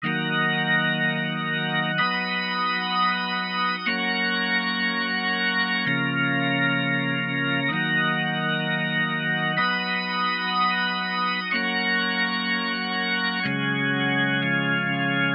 Input Sample (synthesized clean Hammond)